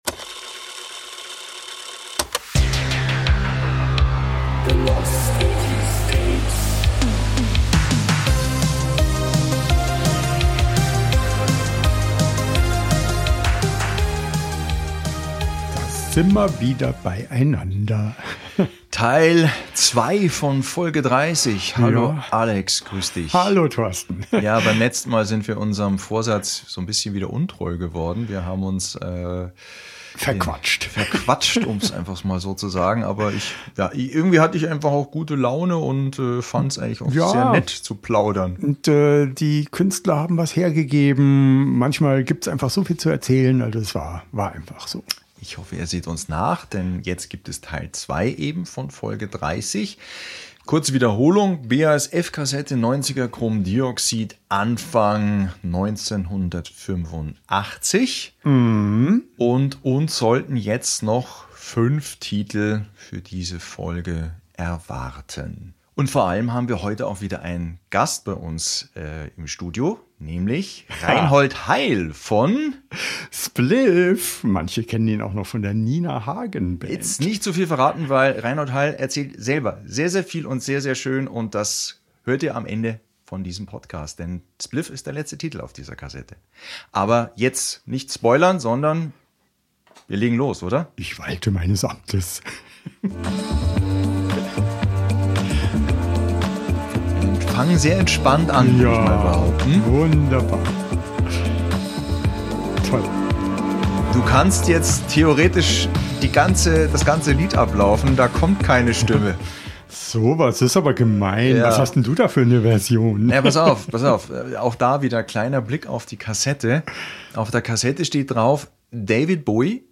Als Highlight gibt es ein sehr persönliches, unterhaltsames und ausführliches Interview mit Reinhold Heil. Wir sprechen mit ihm über seine Anfänge bei der Nina Hagen Band, Spliff und seine erlebnisreiche Zeit danach.